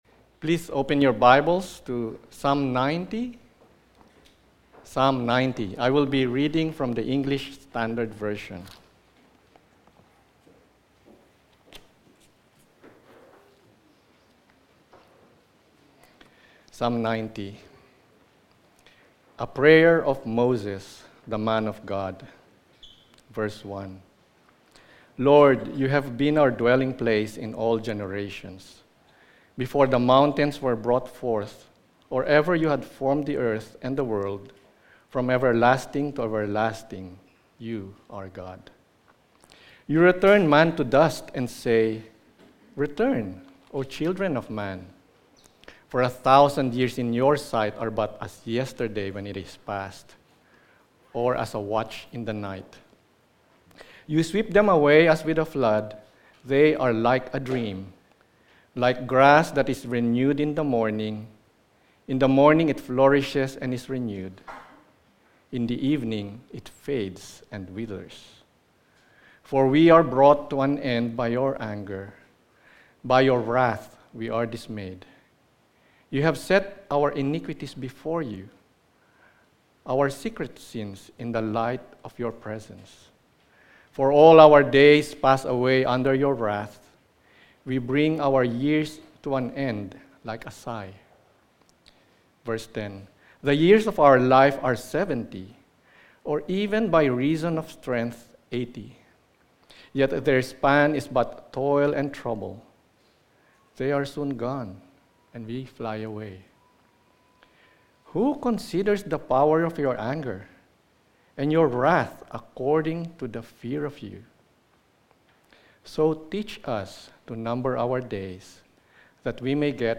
Topical Sermon
Psalm 90:1-17 Service Type: Sunday Morning « Lessons From the Upper Room Series